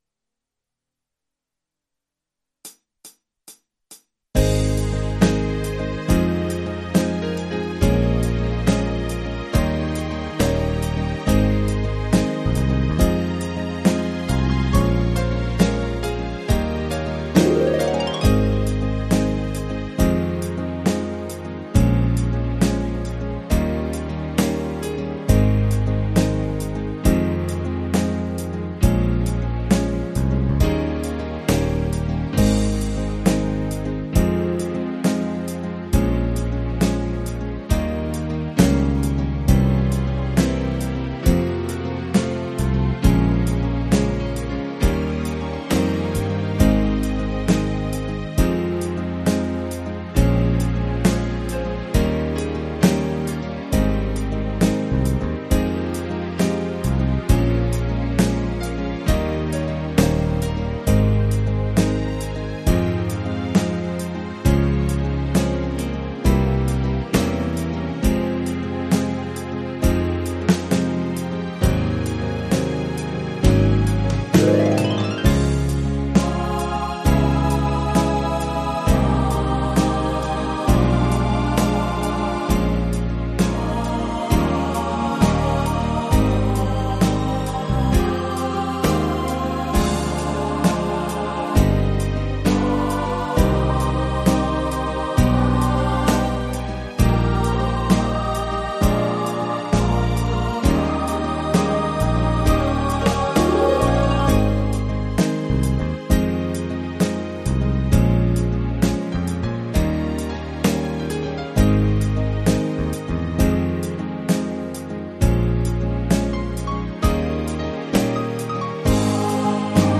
versão instrumental multipista